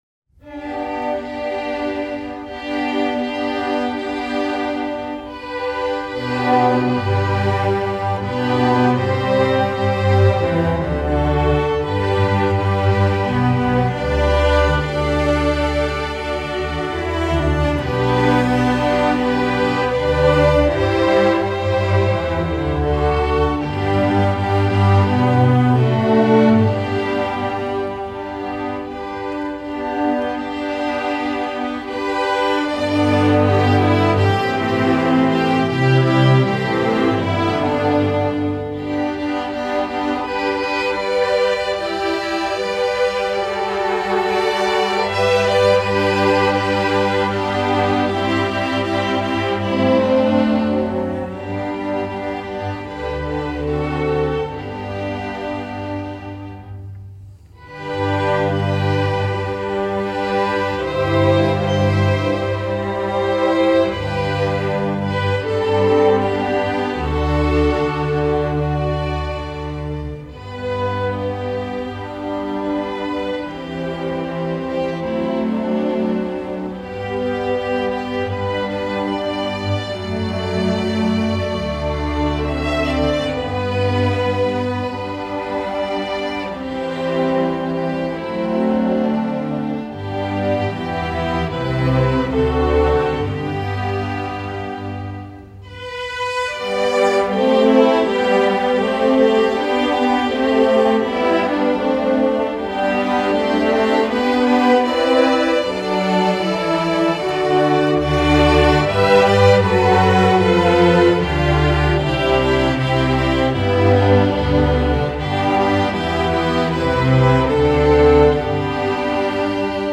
Voicing: SO Set C